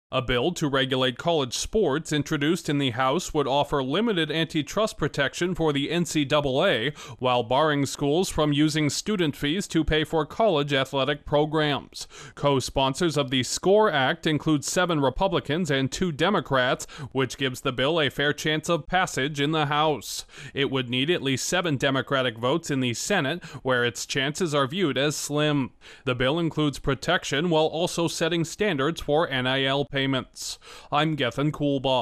Proposed legislation in Congress would prevent schools from using student fees to bankroll college sports. Correspondent